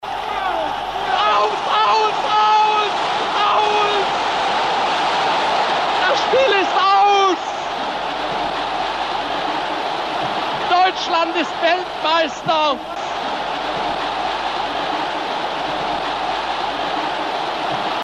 Herbert Zimmermann is the radio commentator of Germany’s first World Cup title.
Herbert Zimmermann is the voice behind one of the most famous pieces of commentary in German History. The journalist couldn’t hold in his excitement after Germany defeated Hungary and won the World Cup, in a match known as “The Miracle of Bern."